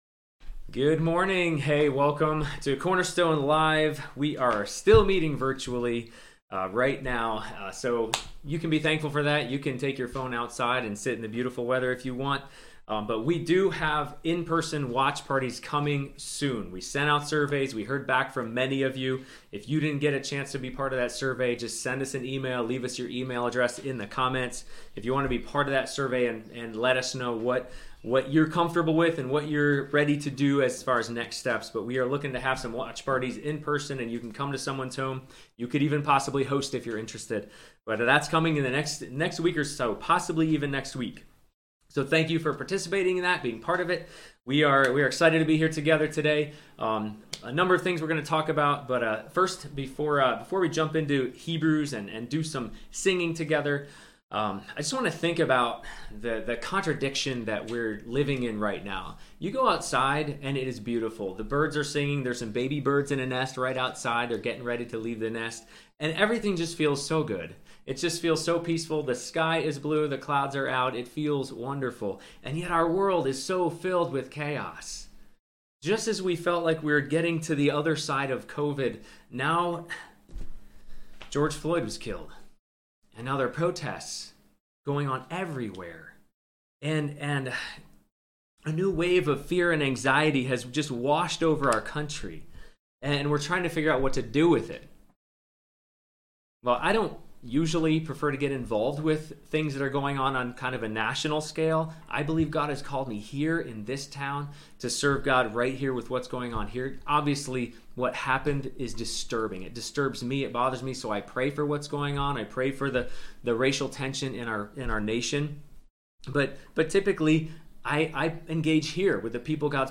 Hebrews 4:14-5:10 Service Type: Sunday Morning Youversion Link What makes Jesus such a great high priest? 1.